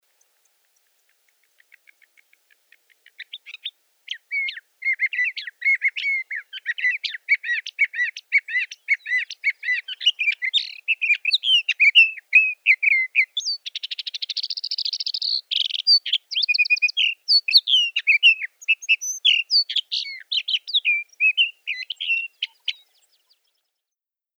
The normal, full-length sage thrasher song from which I extracted the two song sparrow imitations.
Marble Hot Springs, Sierra Valley, California.
115_Sage_Thrasher.mp3